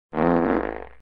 Fart sound effect
Category 🤣 Funny